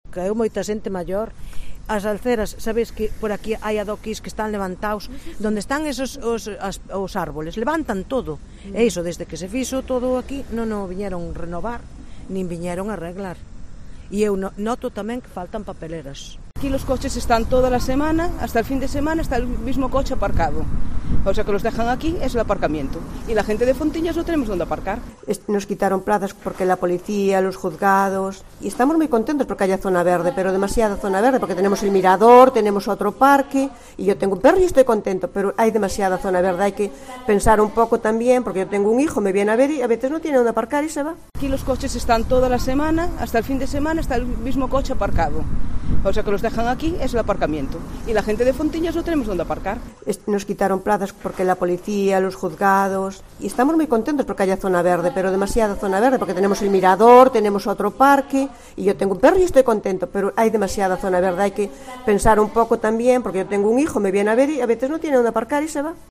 COPE Santiago se pasea por este entorno para charlar con vecinos y comerciantes.